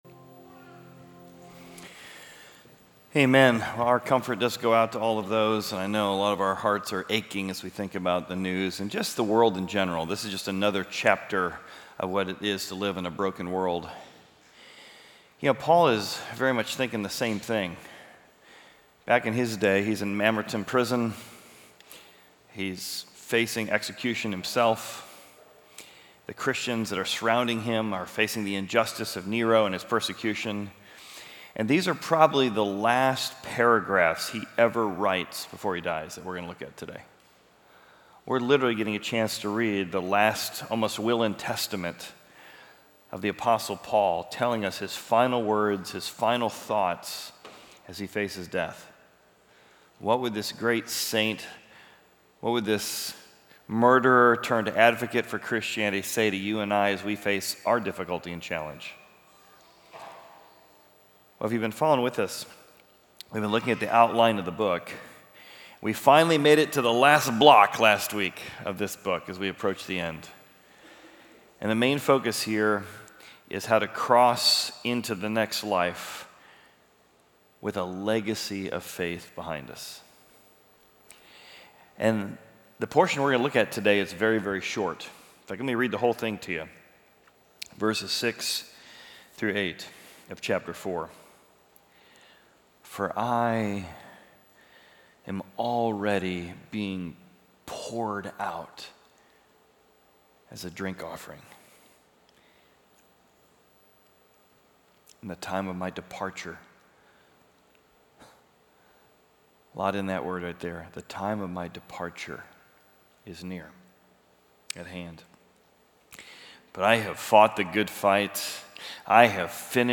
Equipping Service / 2 Timothy: Pass It On / Fight the Good Fight